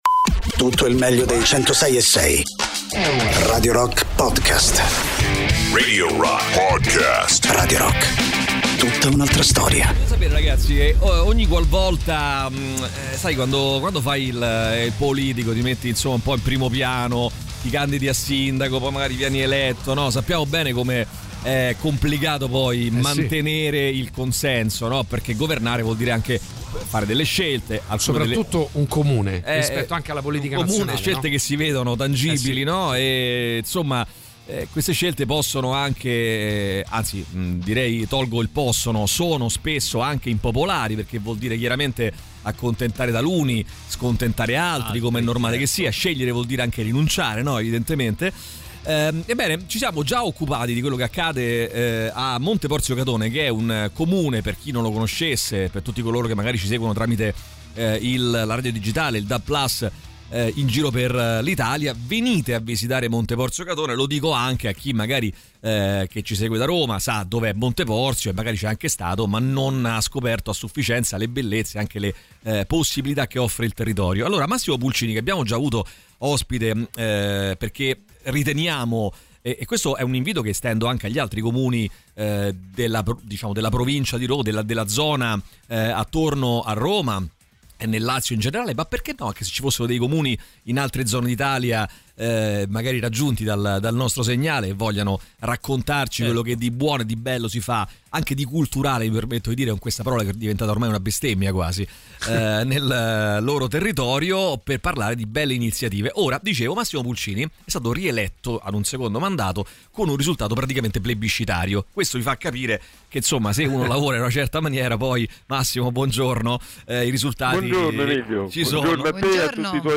Massimo Pulcini, Sindaco di Monte Porzio Catone, ospite telefonico